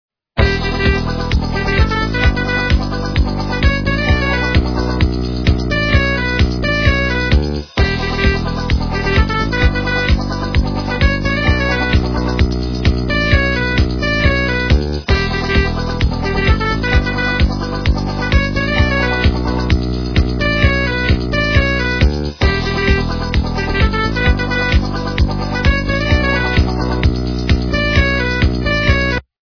- западная эстрада
качество понижено и присутствуют гудки